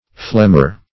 flemer - definition of flemer - synonyms, pronunciation, spelling from Free Dictionary Search Result for " flemer" : The Collaborative International Dictionary of English v.0.48: Flemer \Flem"er\, n. One who, or that which, banishes or expels.